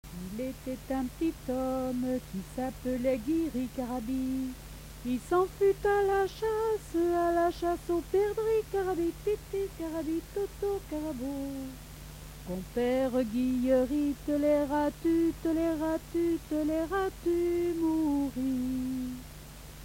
Enfantines - rondes et jeux
Pièce musicale inédite